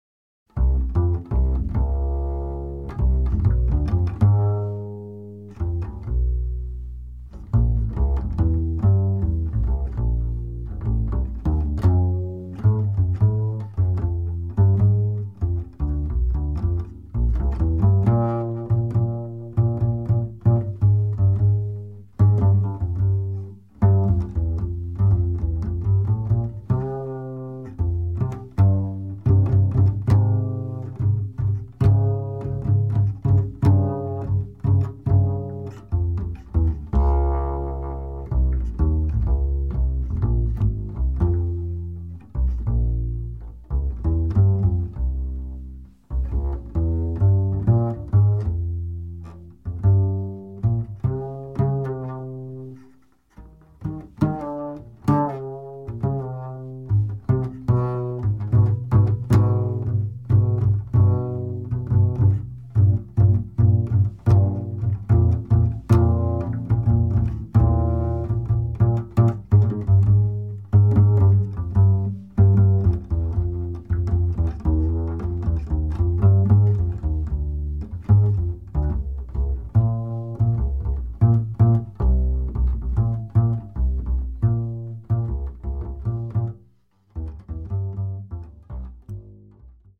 Jazz pianist
piano
bass
drums